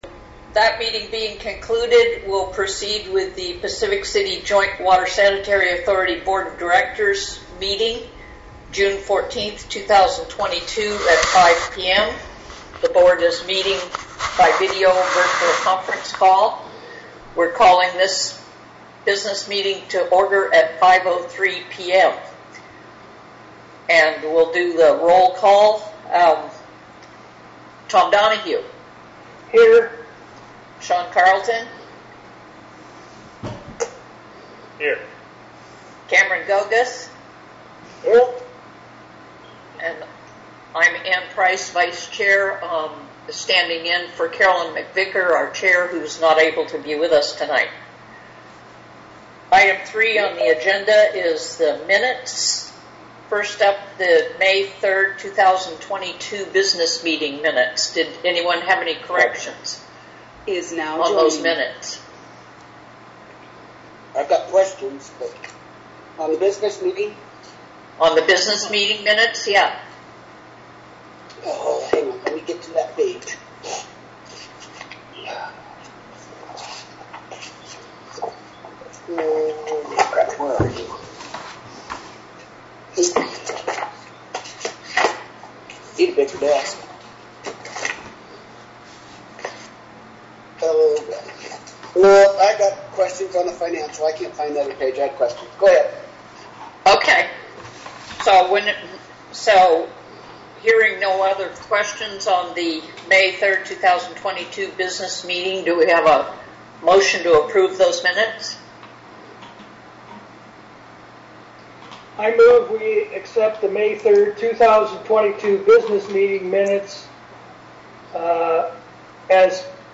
Budget Adoption Meeting